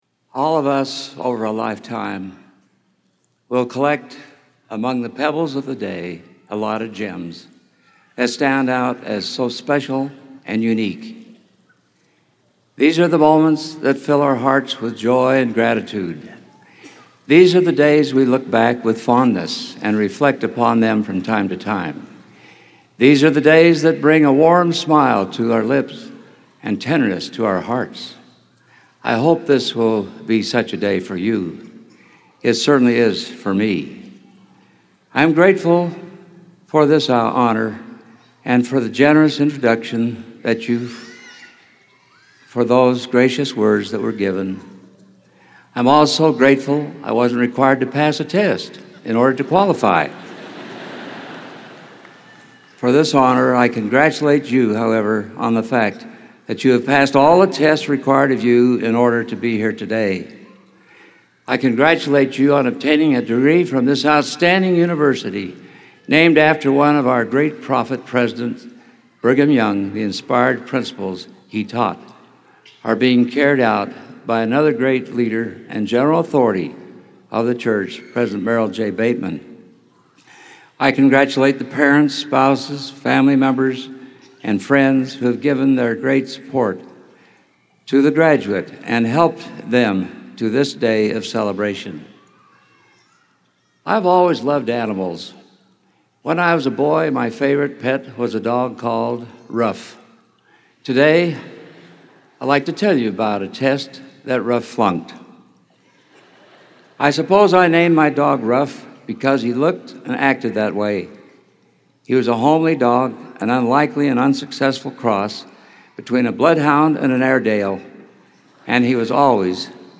Commencement